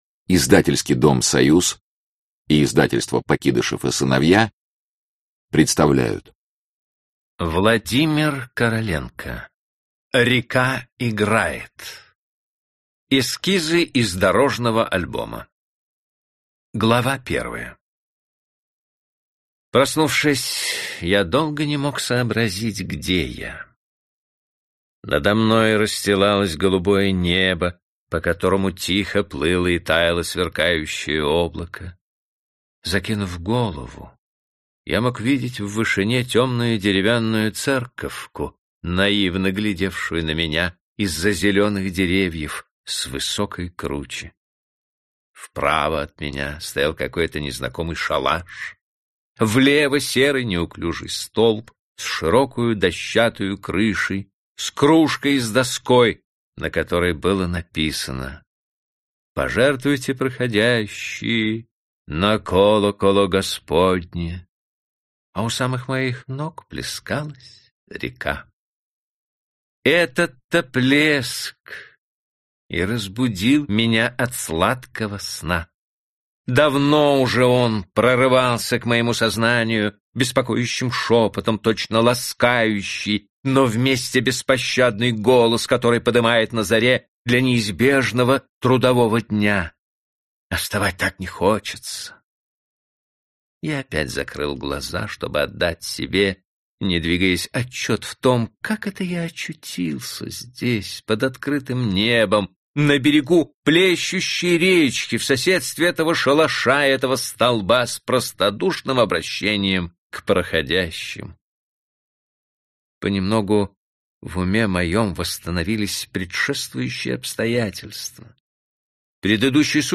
Аудиокнига Река играет | Библиотека аудиокниг
Прослушать и бесплатно скачать фрагмент аудиокниги